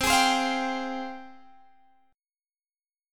G#M13/C Chord
Listen to G#M13/C strummed